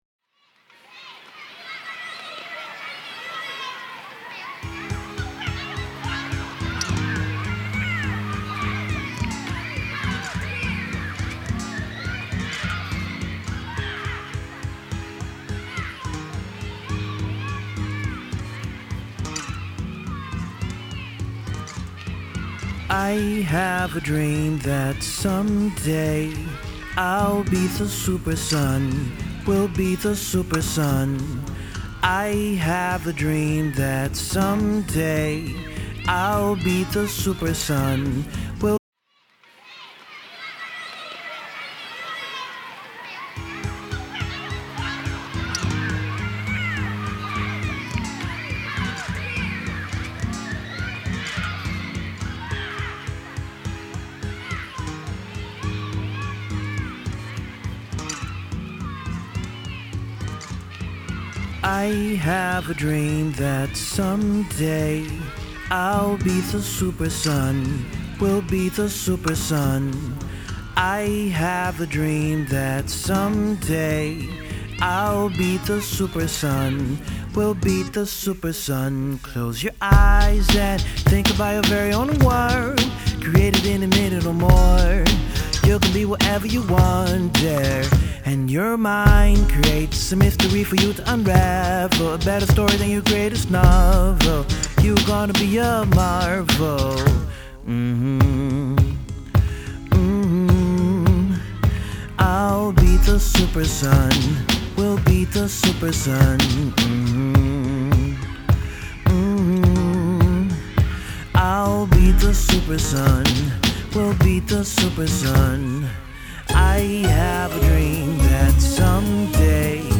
neo-soul